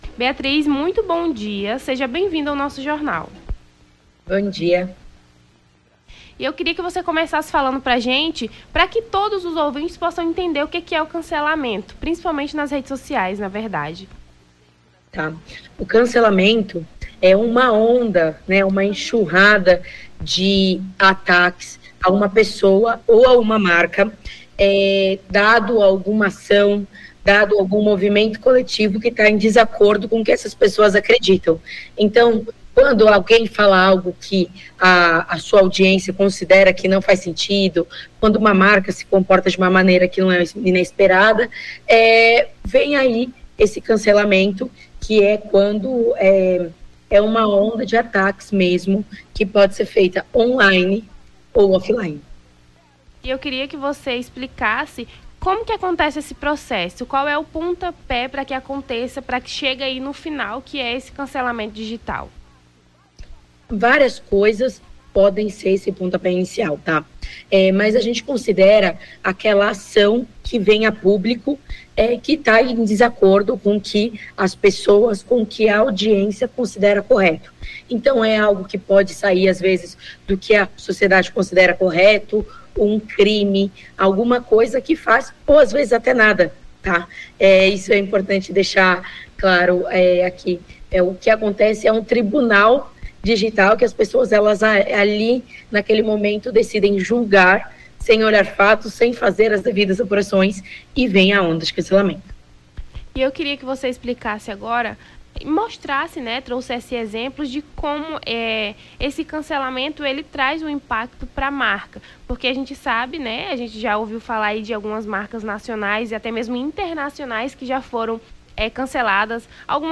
as apresentadoras
conversam com a especialista em marketing digital
Nome do Artista - CENSURA - ENTREVISTA (CANCELAMENTO DIGITAL) 09-10-23.mp3